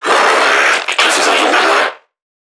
NPC_Creatures_Vocalisations_Infected [47].wav